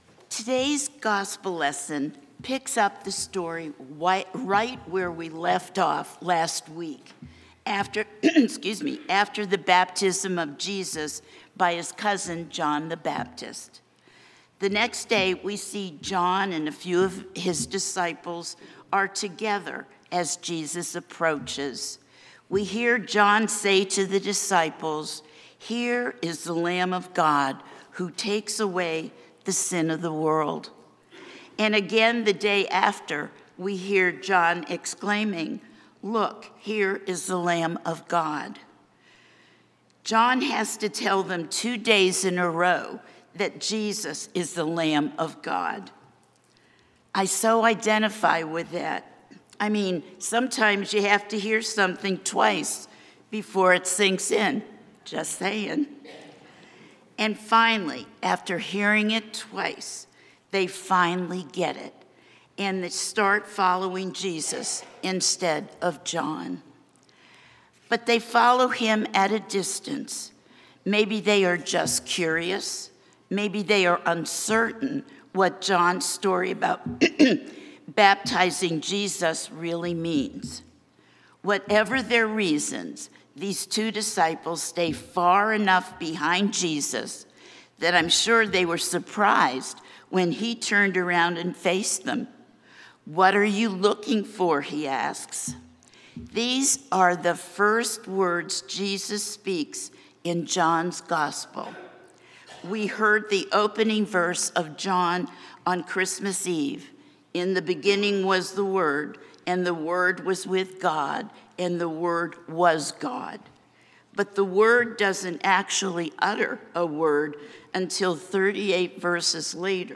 St-Pauls-HEII-9a-Homily-18JAN26.mp3